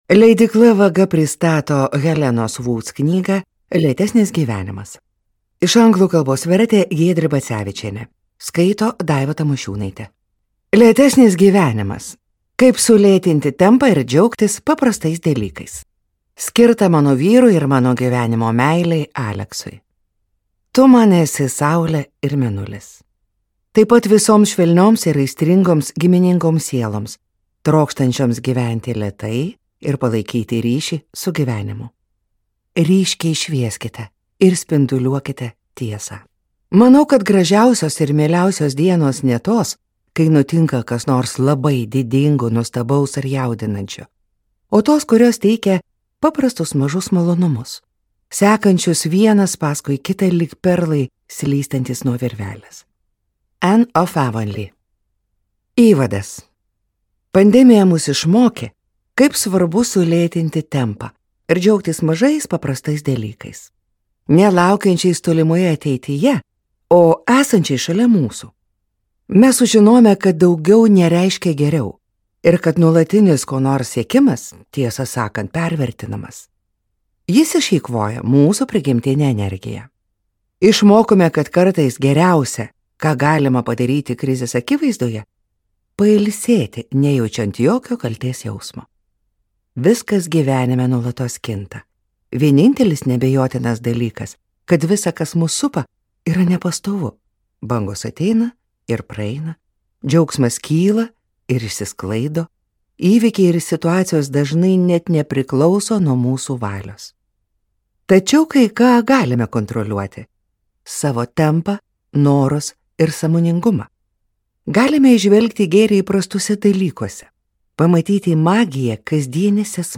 Lėtesnis gyvenimas: kaip sulėtinti gyvenimo tempą ir visur pastebėti džiaugsmą | Audioknygos | baltos lankos